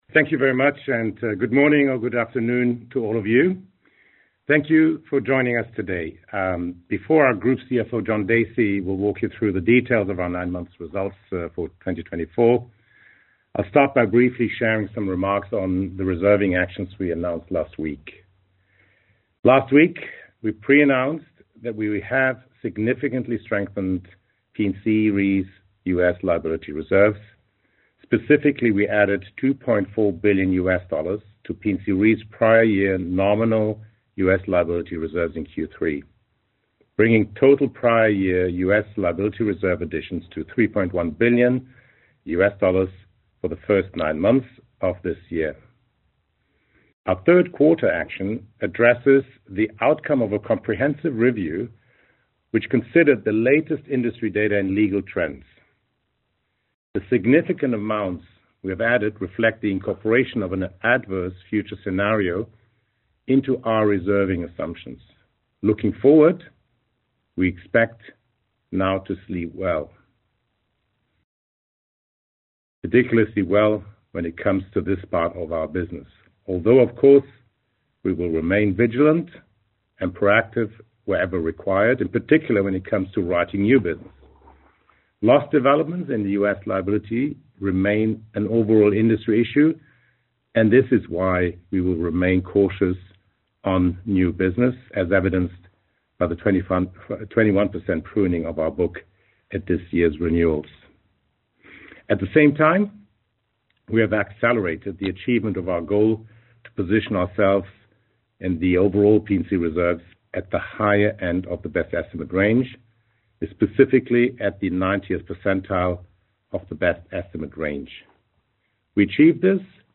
Nine Months 2024 Results, Conference Call | Swiss Re
9m-2024-call-recording.mp3